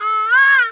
pgs/Assets/Audio/Animal_Impersonations/bird_peacock_squawk_soft_02.wav at master
bird_peacock_squawk_soft_02.wav